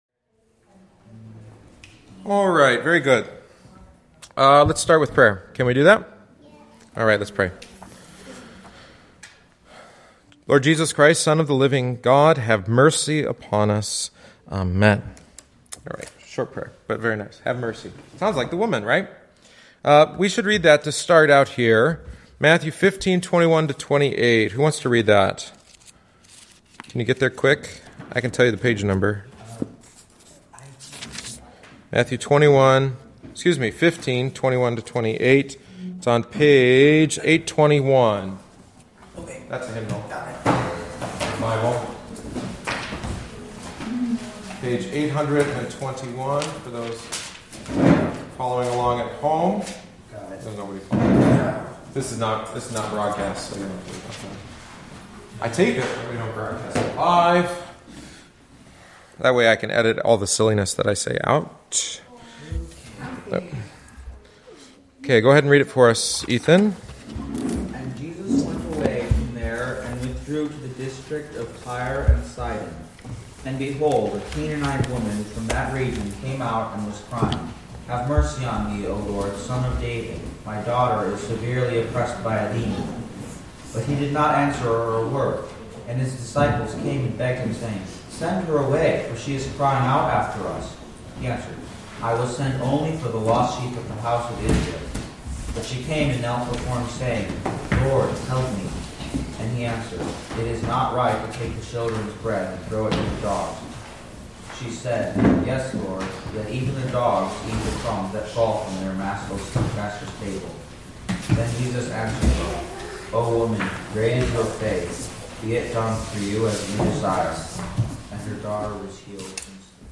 Catechesis on the Canaanite Woman